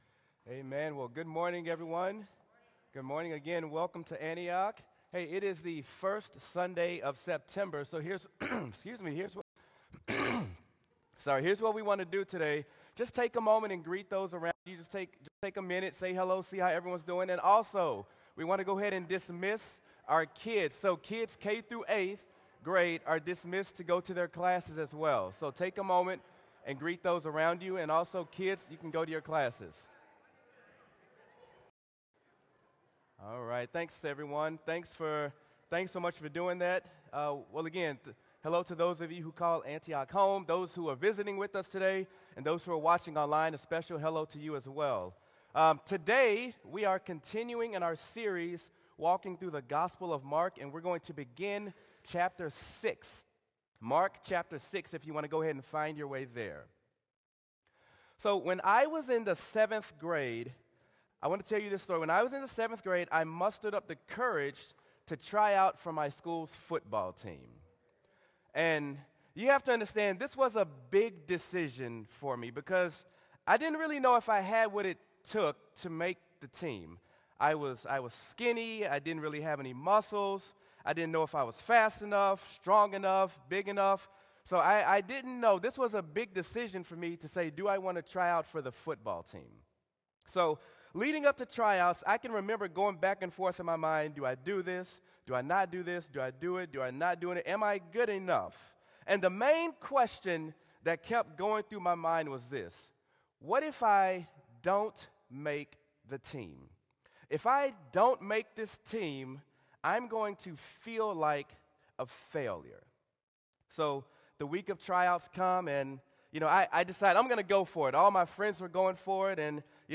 Sermon: Mark: Facing Rejection